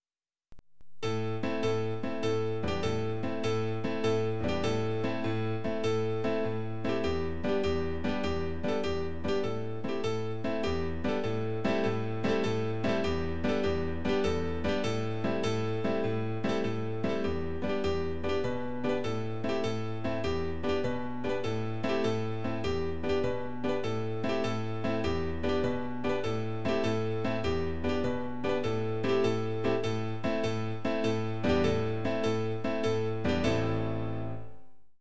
Instrumental Song / Instrumental